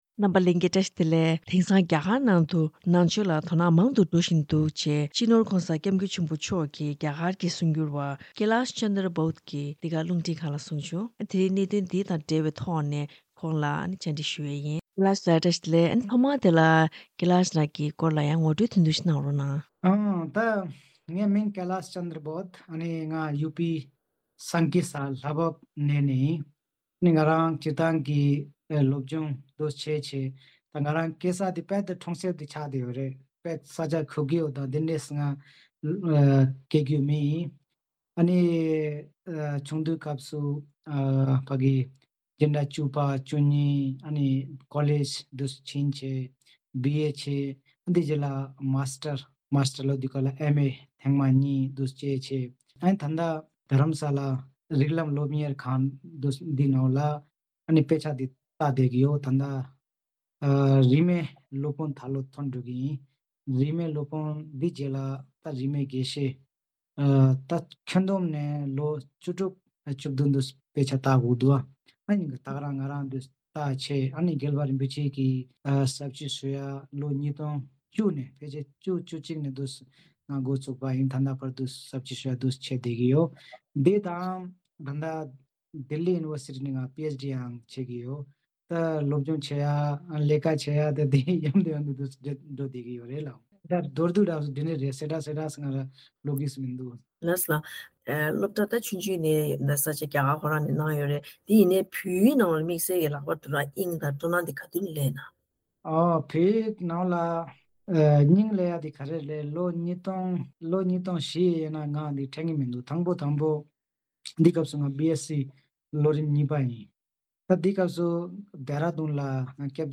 བཀའ་དྲི་ཞུས་པ་ཞིག་གསན་རོགས་གནང་།